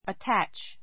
ətǽtʃ